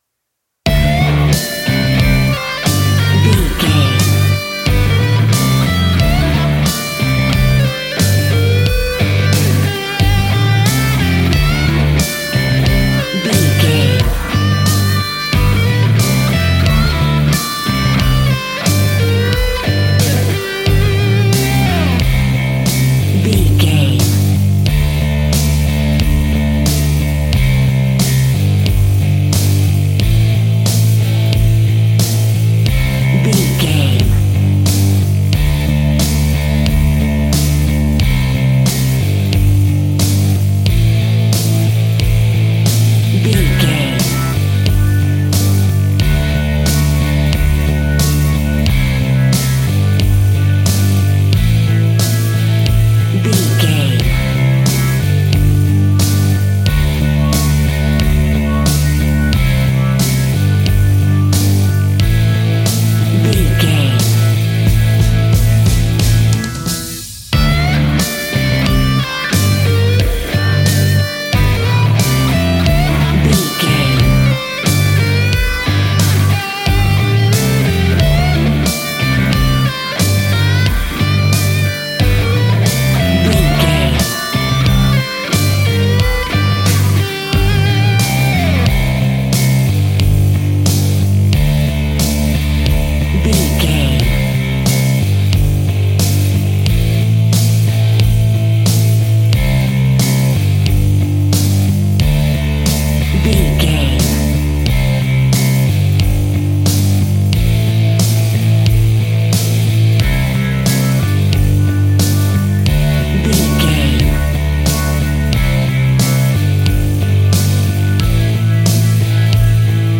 Epic / Action
Fast paced
Mixolydian
hard rock
blues rock
distortion
instrumentals
rock guitars
Rock Bass
Rock Drums
heavy drums
distorted guitars
hammond organ